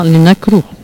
Catégorie Locution ( parler, expression, langue,... )